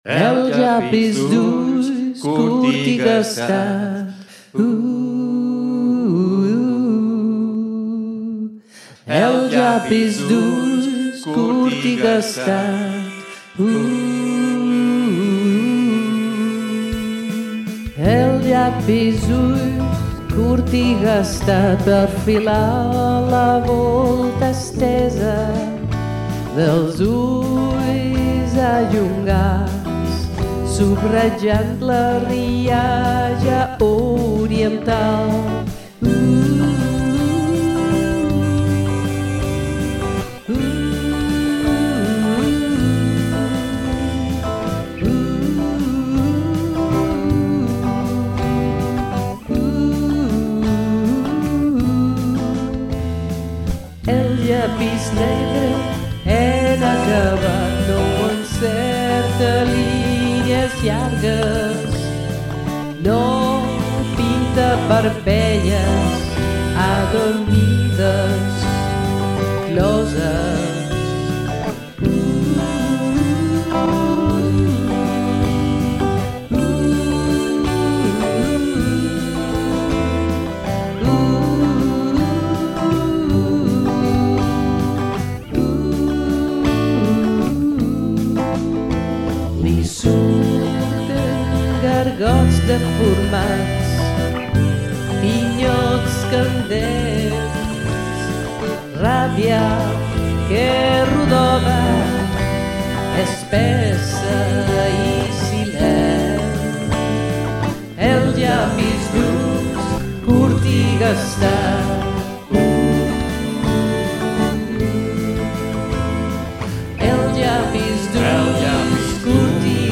un  poema musicalizado